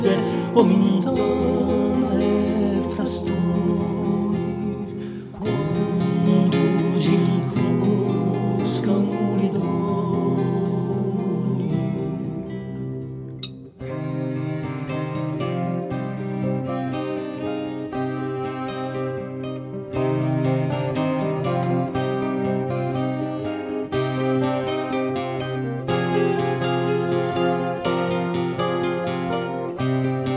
polyphonie
Musique du Monde